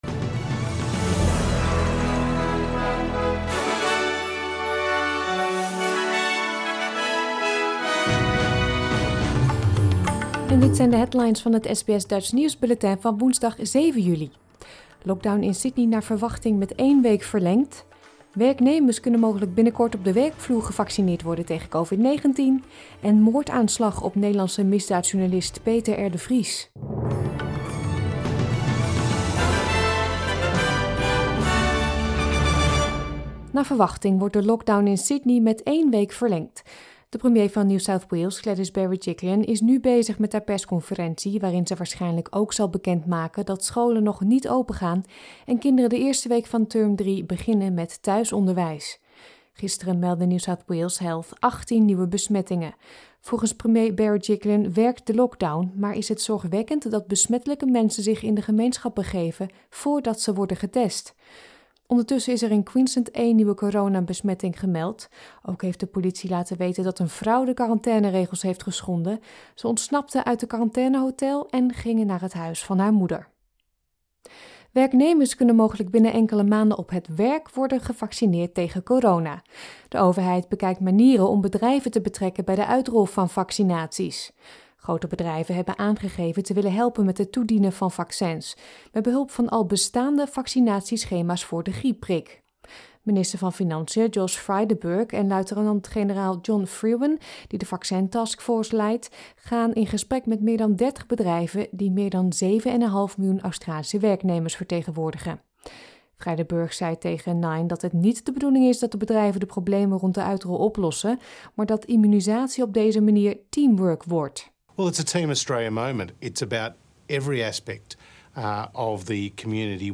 Nederlands/Australisch SBS Dutch nieuwsbulletin van woensdag 7 juli 2021